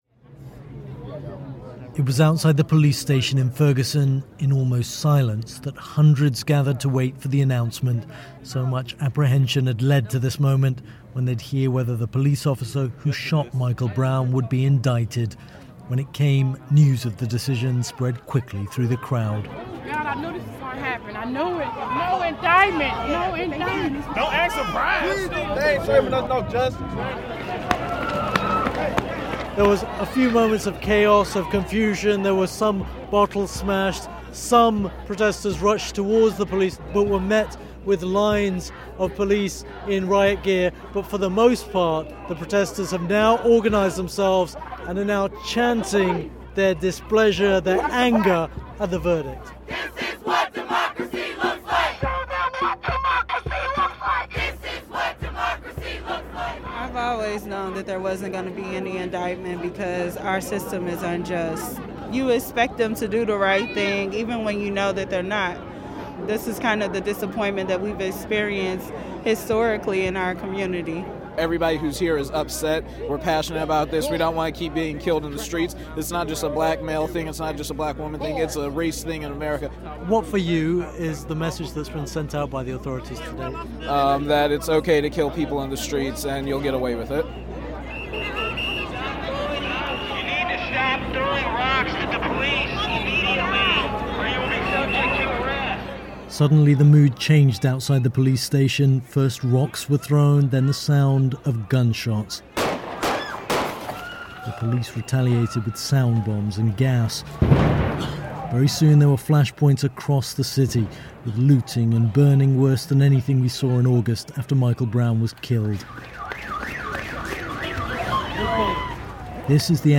Our report on the streets as Ferguson learned white police officer, Darren Wilson, wouldn't be charged for shooting dead black teenager Michael Brown